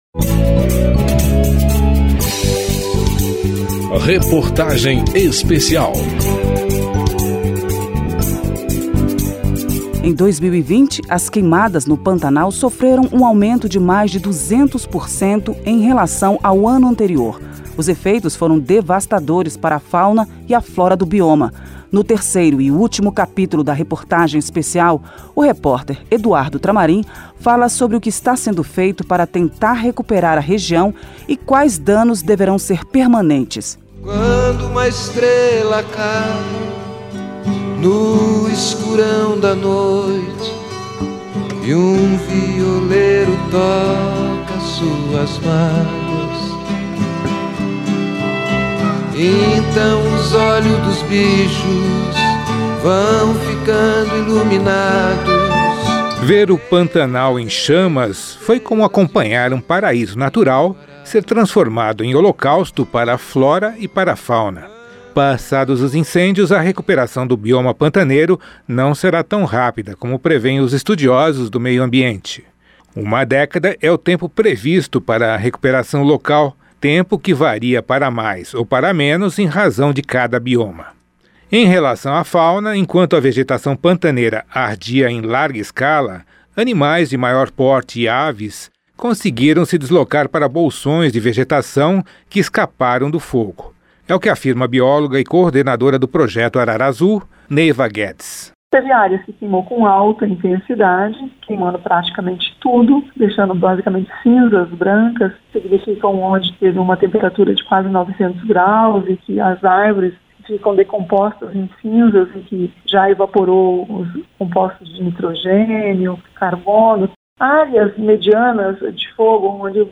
Reportagem Especial